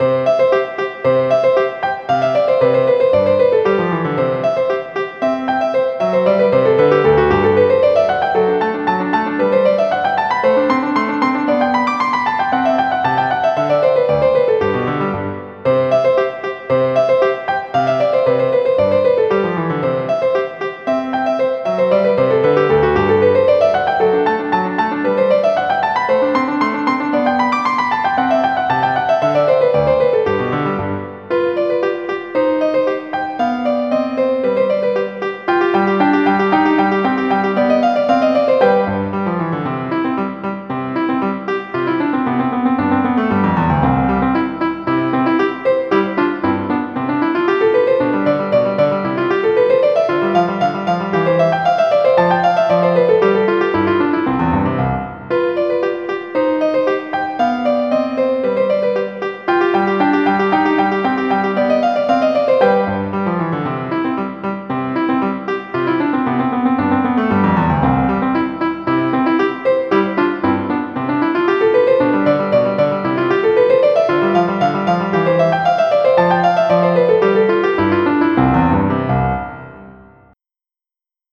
MIDI Music File
Type General MIDI
sonatina.mp3